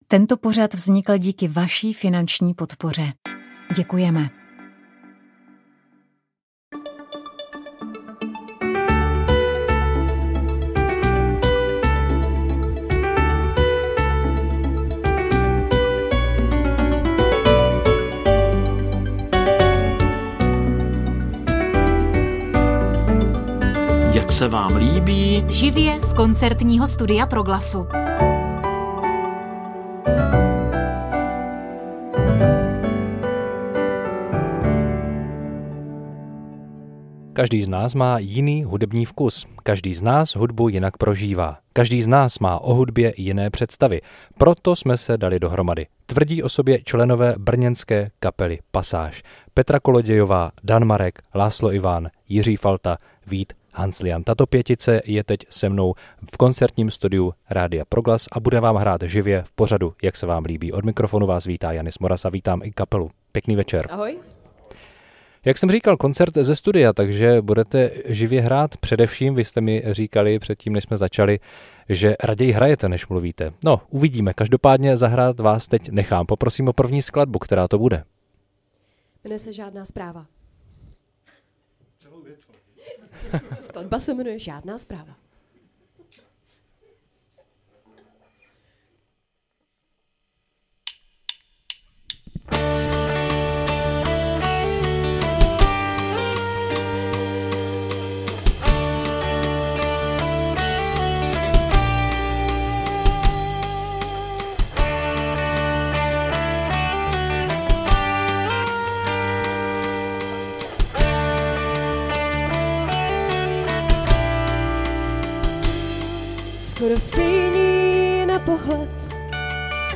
Rozhovor s Danem Bártou